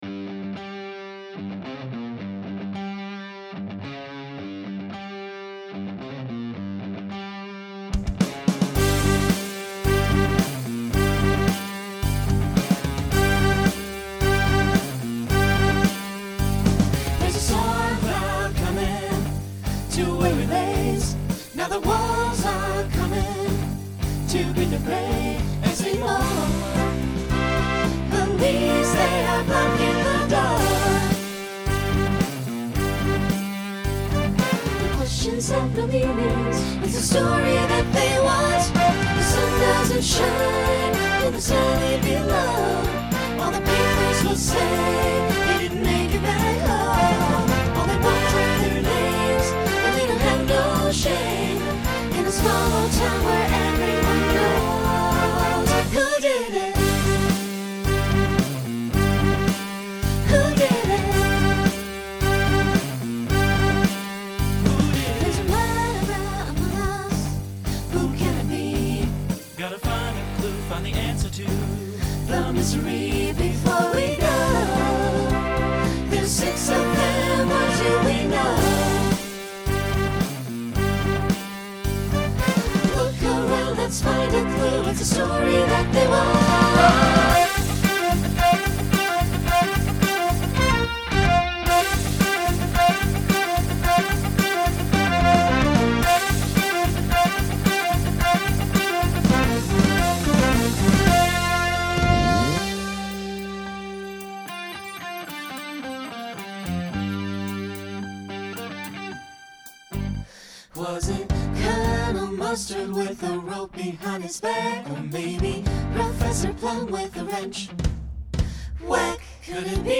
Genre Rock
Story/Theme Voicing SATB